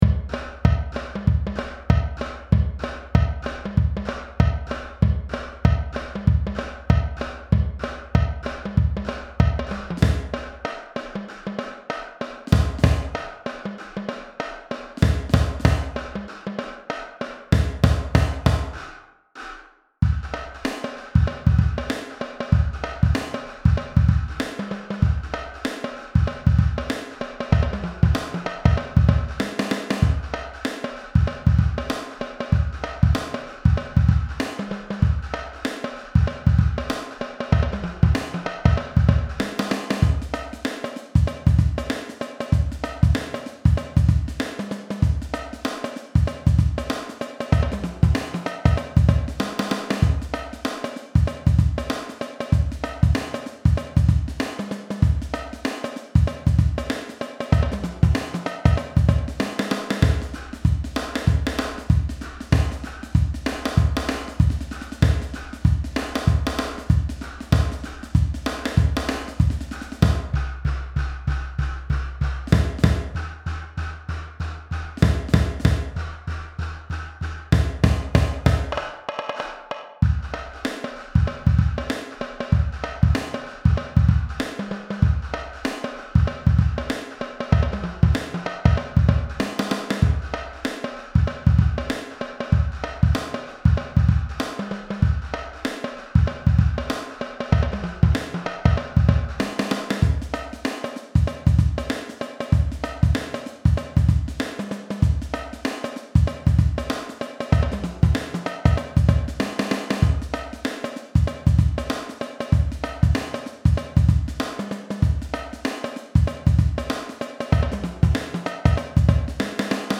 funky drumline